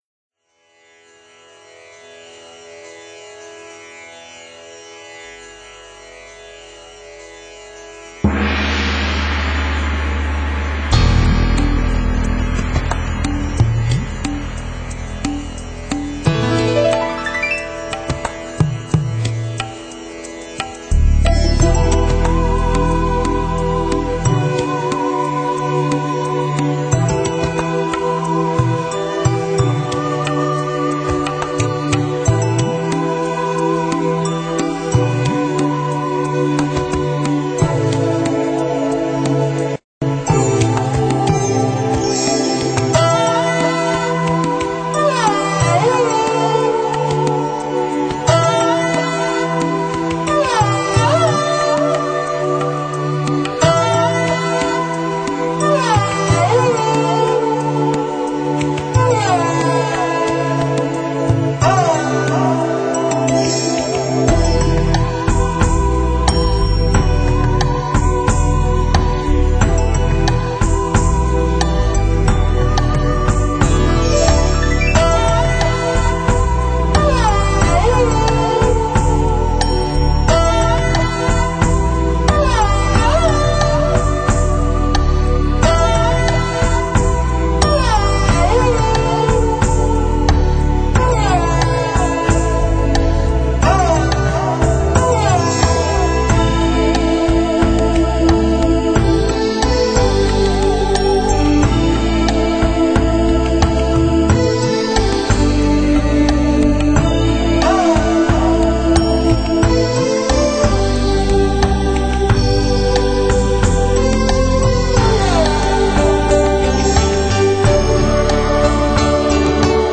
专辑语言：纯音乐